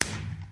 firework1.mp3